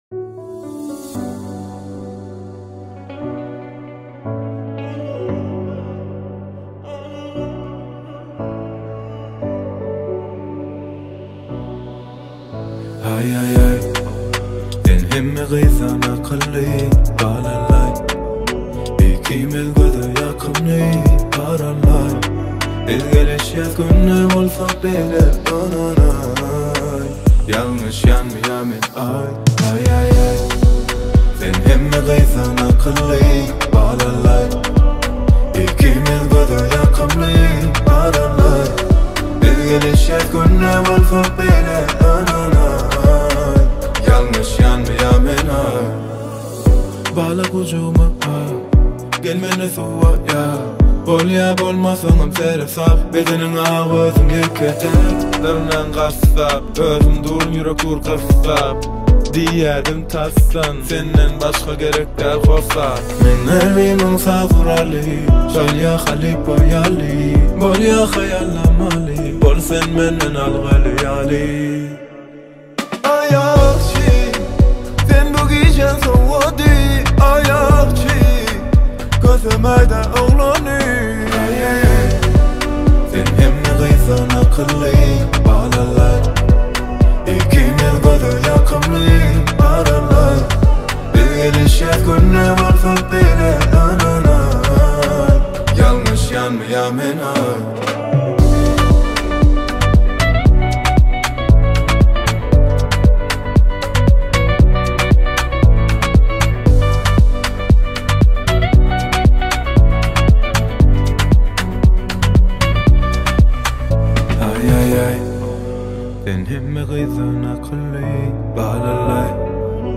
узбекская музыка